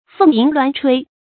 鳳吟鸞吹 注音： ㄈㄥˋ ㄧㄣˊ ㄌㄨㄢˊ ㄔㄨㄟ 讀音讀法： 意思解釋： 比喻極為美妙的歌聲。